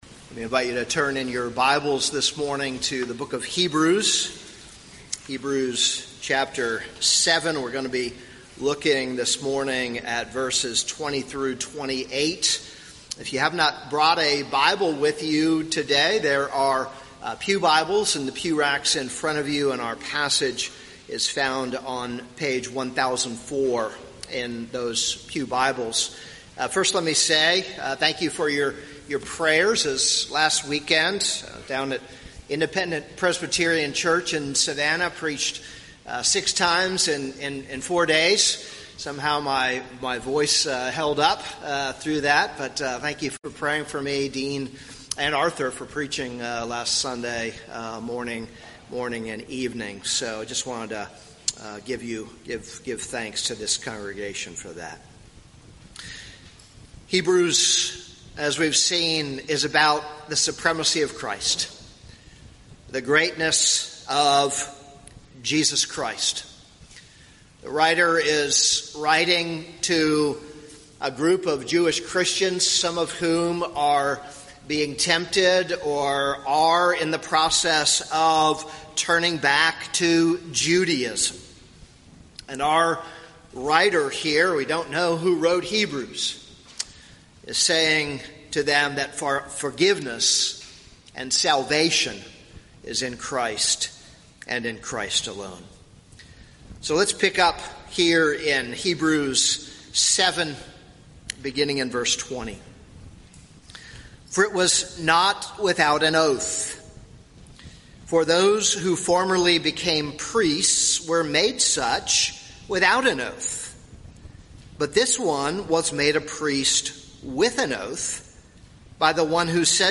This is a sermon on Hebrews 7:20-28.